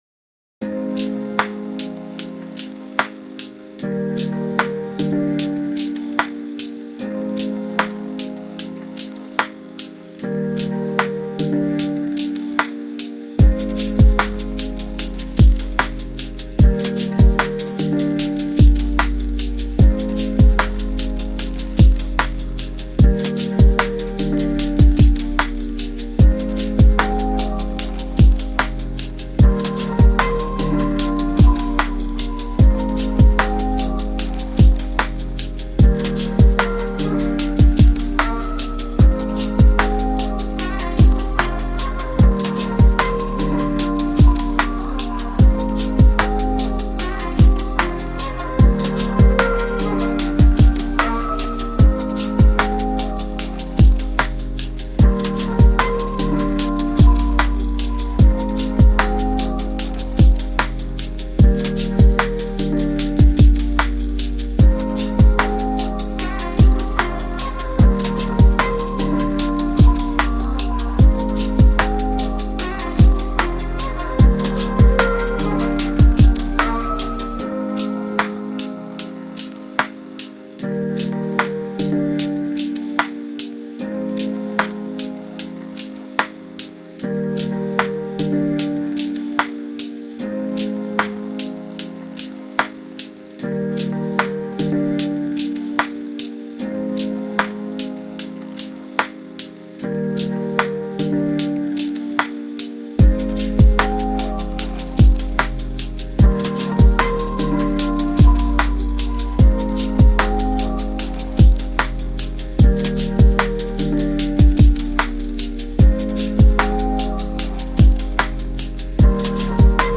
ListenWatch on YouTube  Chillout, chill hop